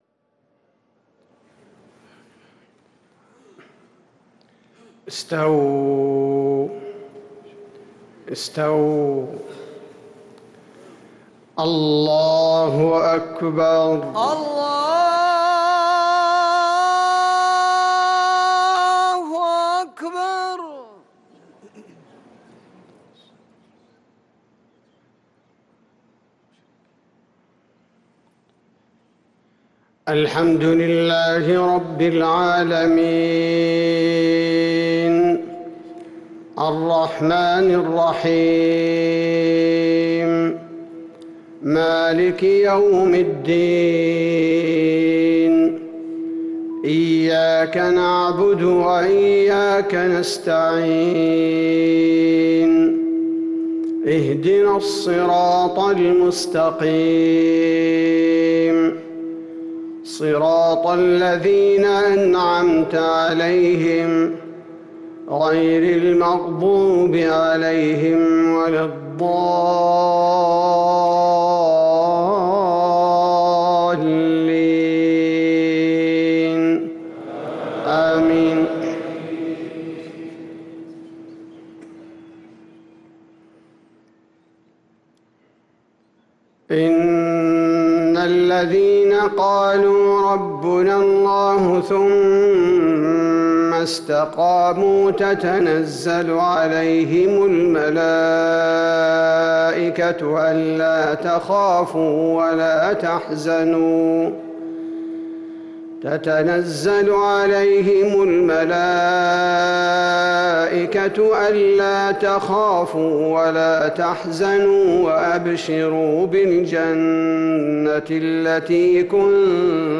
صلاة المغرب للقارئ عبدالباري الثبيتي 10 ذو الحجة 1444 هـ
تِلَاوَات الْحَرَمَيْن .